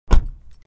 جلوه های صوتی
دانلود صدای ماشین 27 از ساعد نیوز با لینک مستقیم و کیفیت بالا
برچسب: دانلود آهنگ های افکت صوتی حمل و نقل دانلود آلبوم صدای انواع ماشین از افکت صوتی حمل و نقل